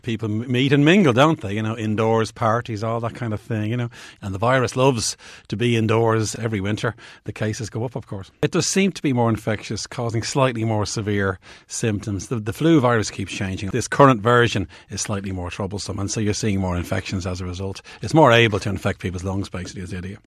Immunologist, Professor Luke O’Neill says this time of year creates a “perfect storm” for flu spread……..